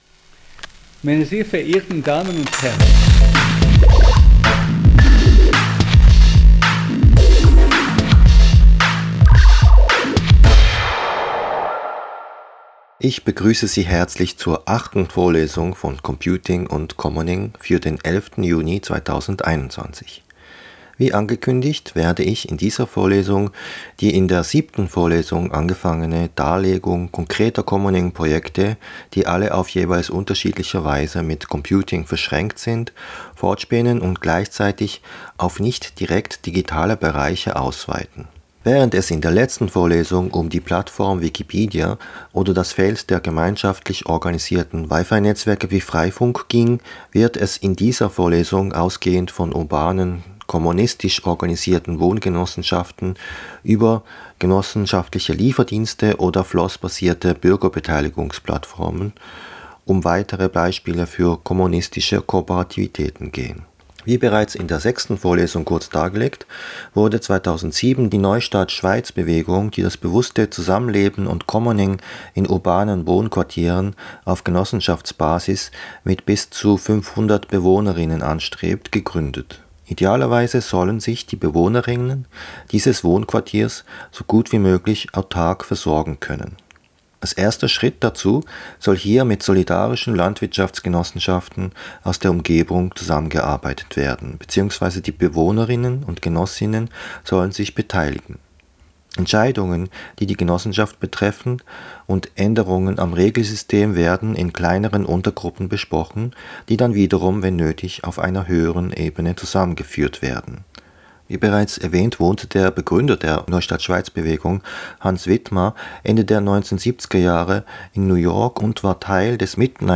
Podcastvorlesung